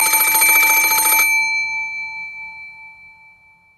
You are woken up by the sound of a phone.
phone.mp3